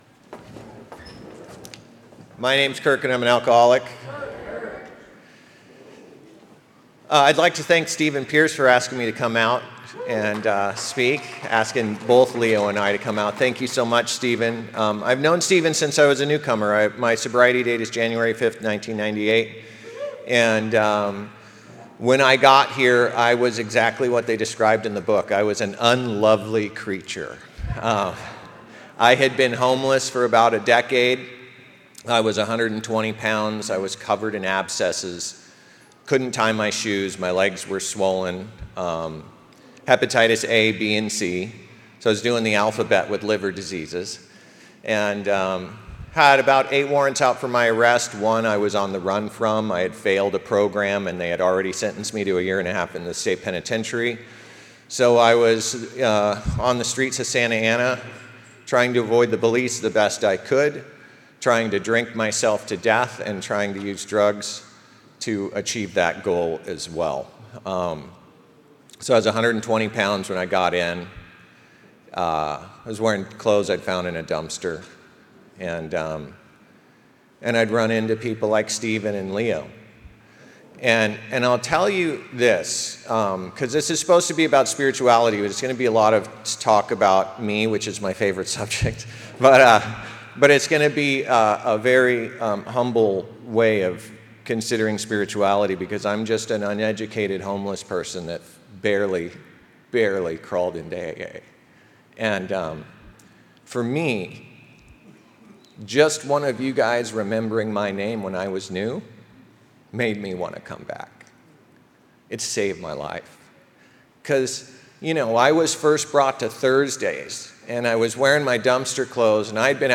35th Indian Wells Valley Roundup &#8211